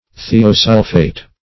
Search Result for " thiosulphate" : The Collaborative International Dictionary of English v.0.48: Thiosulphate \Thi`o*sul"phate\, n. (Chem.)
thiosulphate.mp3